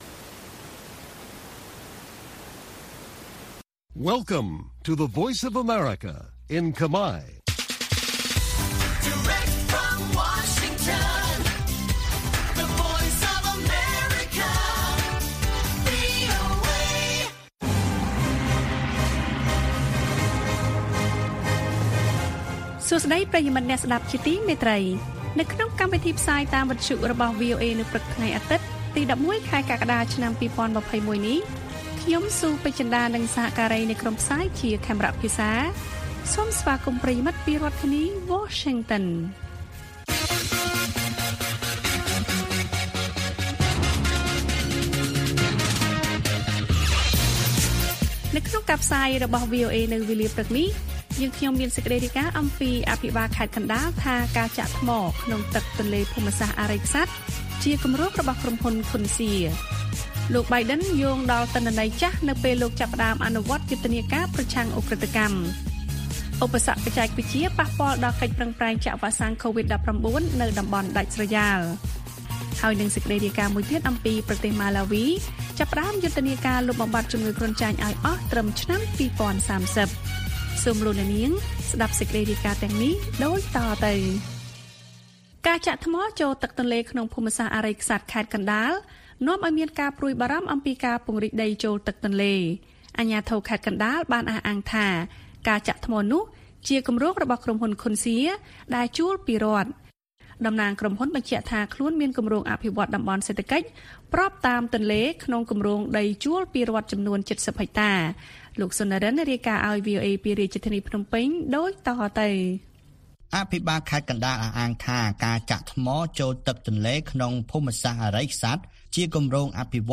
ព័ត៌មានពេលព្រឹក៖ ១១ កក្កដា ២០២១